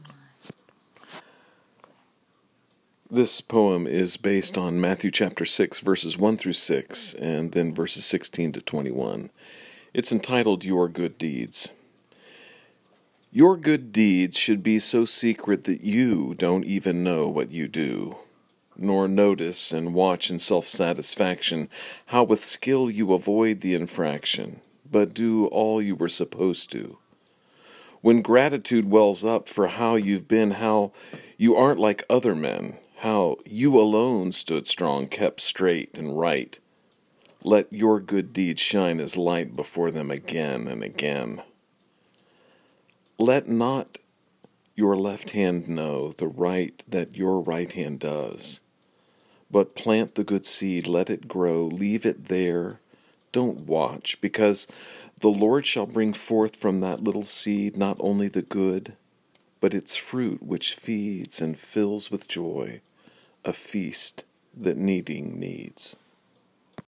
If it’s helpful, you may listen to me read the sonnet via the player below.